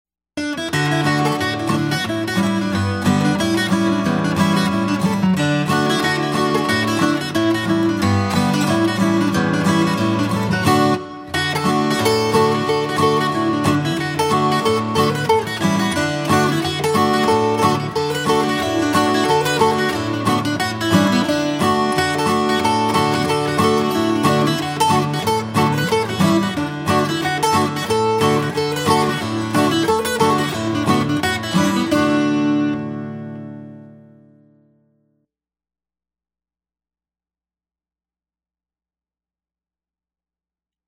DIGITAL SHEET MUSIC - FLATPICK/PLECTRUM GUITAR SOLO